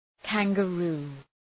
Προφορά
{,kæŋgə’ru:}